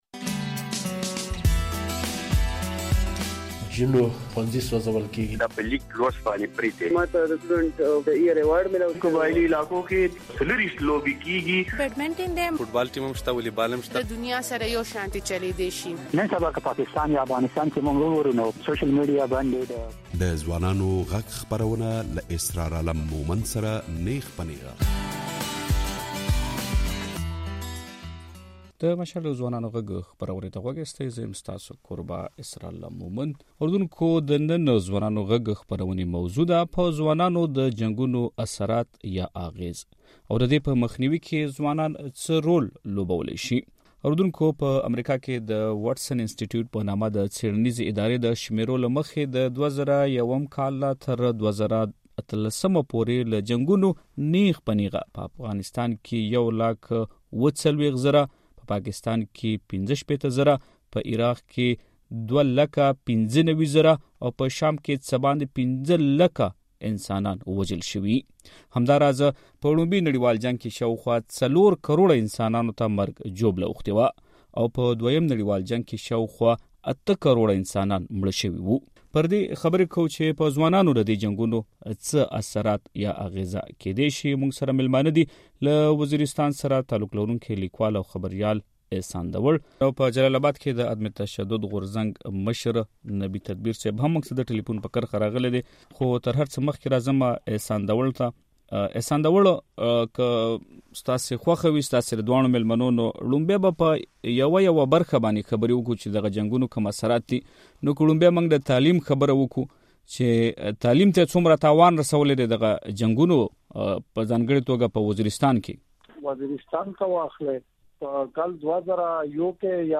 د دې اوونۍ د ځوانانو غږ خپرونې موضوع وه «پر ځوانانو د جنګونو اغېز» او په دې مو له ځوانانو سره بحث درلود چې ځوانان په دې لاره کې څه رول لوبولی شي؟